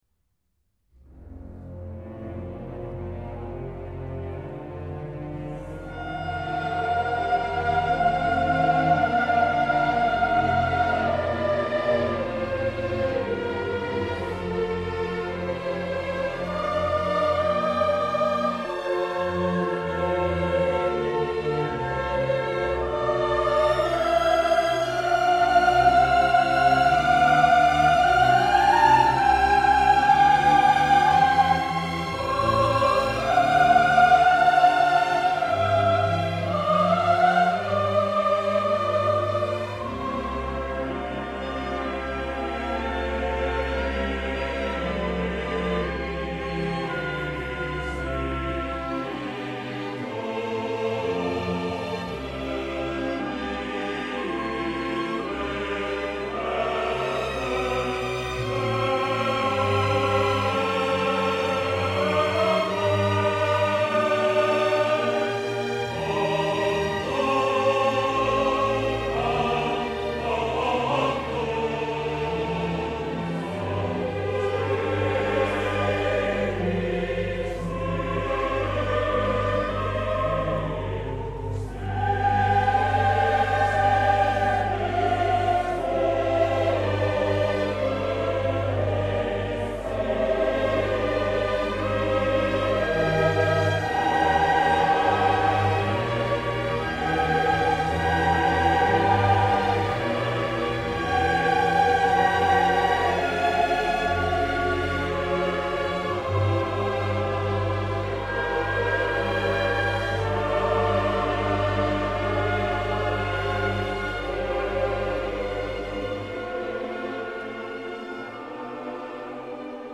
Intervista a Markus Poschner